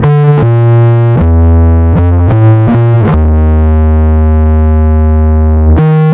Track 07 - Bass 01.wav